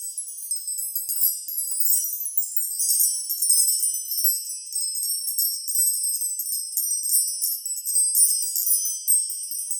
magic_sparkle_gem_loop_04.wav